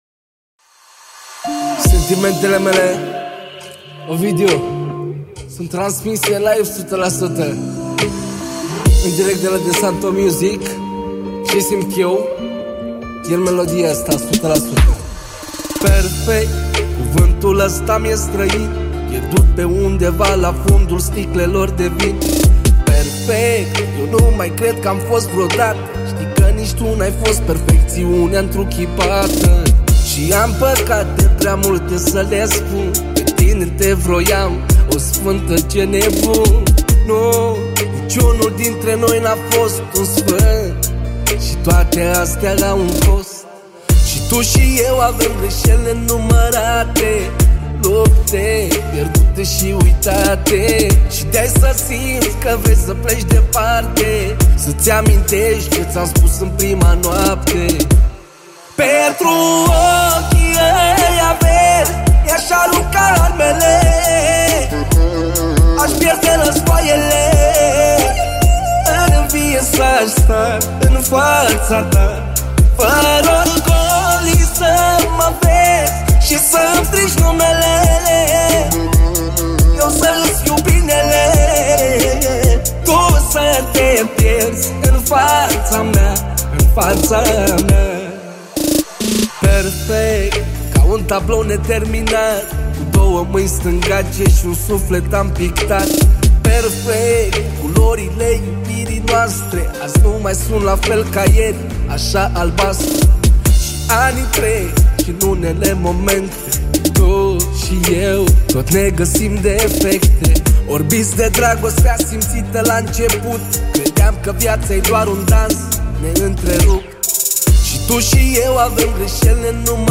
Data: 08.10.2024  Manele New-Live Hits: 0